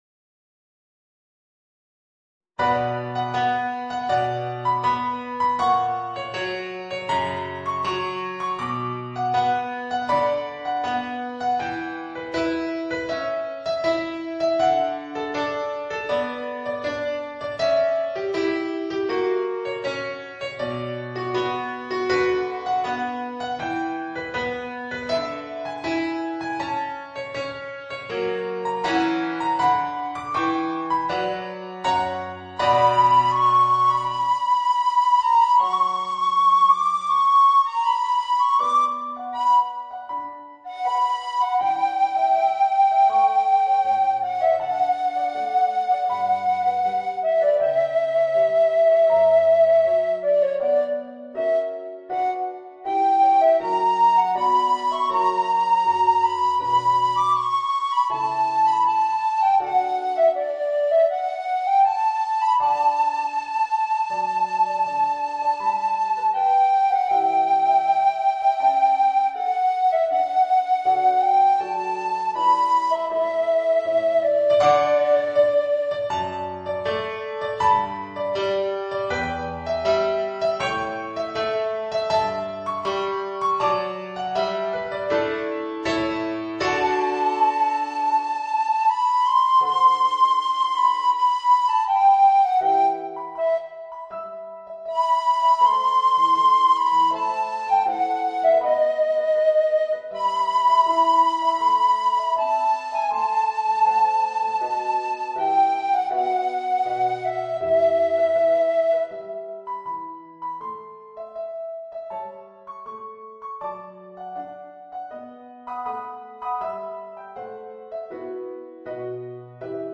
Voicing: Alto Recorder and Organ